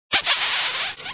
kiss_x.wav